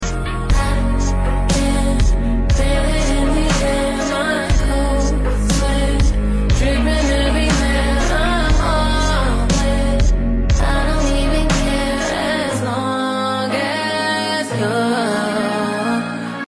intense, passionate and dreamy new 2025 R&B love song